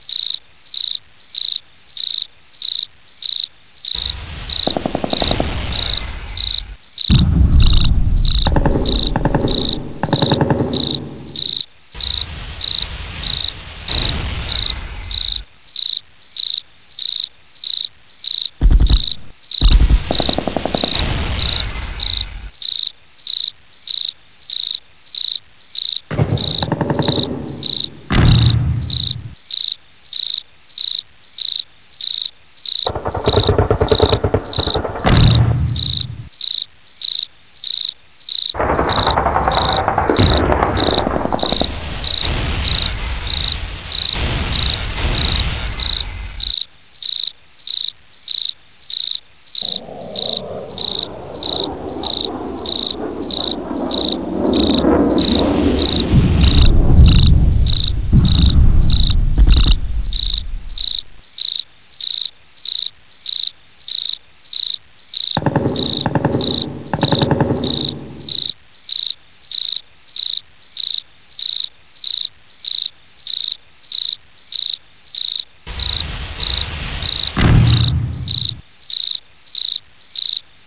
cricket.wav